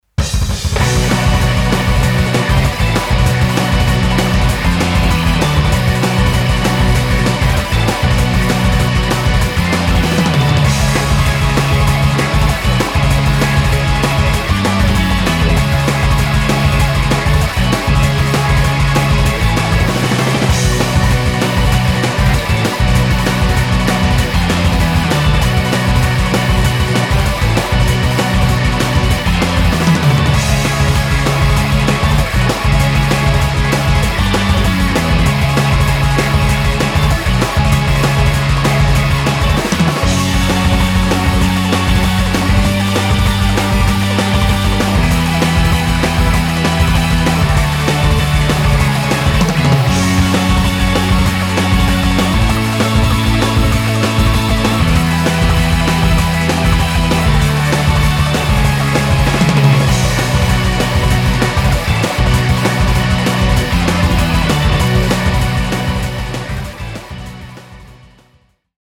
フリーBGM イベントシーン 緊張感
フェードアウト版のmp3を、こちらのページにて無料で配布しています。